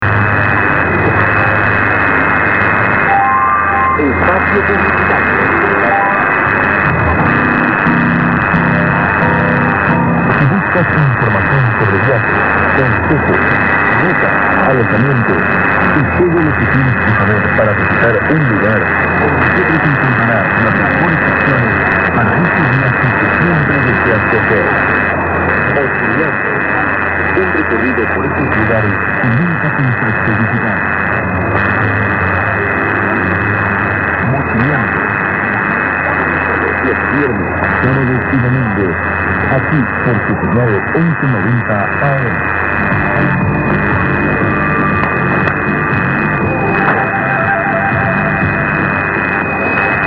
mx stn. tk in ss 0414. local qrn as well. 2/5